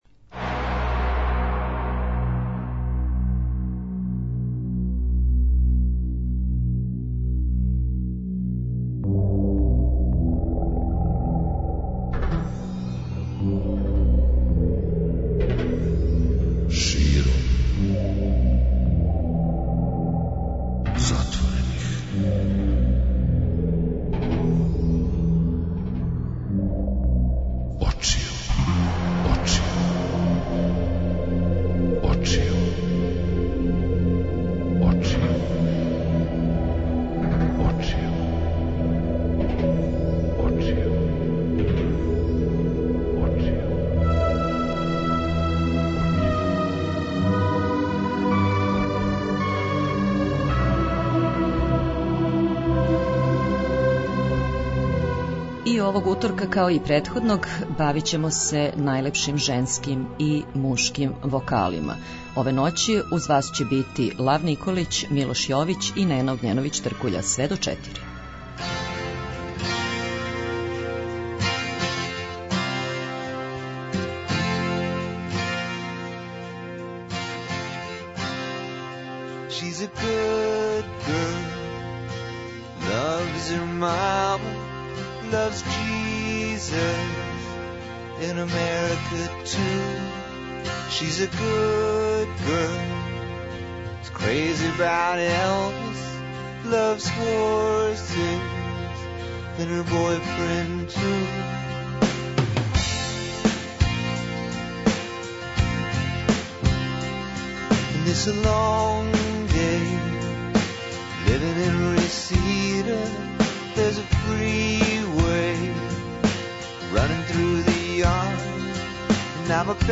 Настављамо ноћно дружење са најбољим женским и мушким вокалима са наше и иностране сцене, уз много добре и квалитетне музике, од поноћи па све до раних јутарњих сати.
преузми : 56.53 MB Широм затворених очију Autor: Београд 202 Ноћни програм Београда 202 [ детаљније ] Све епизоде серијала Београд 202 We care about disco!!!